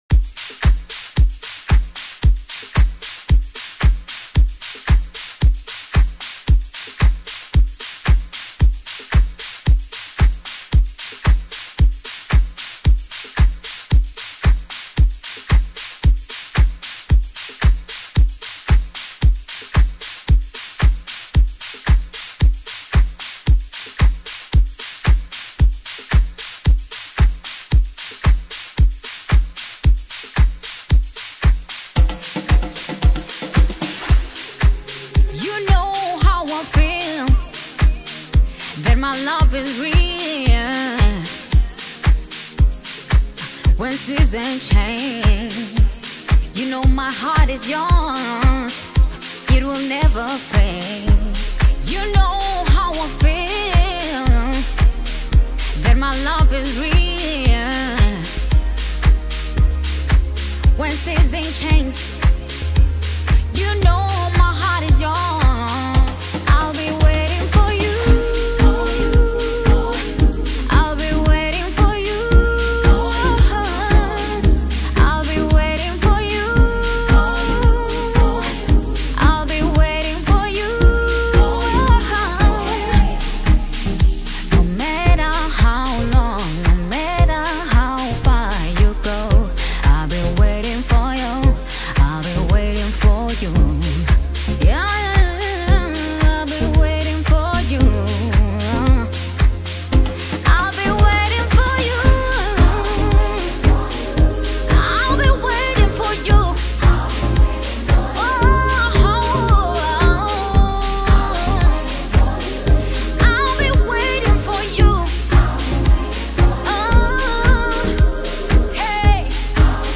Amapiano Gospel
Genre: Gospel/Christian.